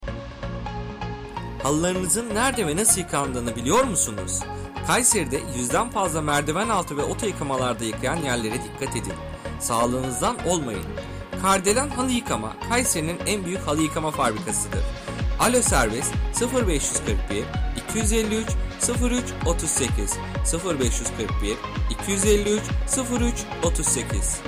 Sesli Mesaj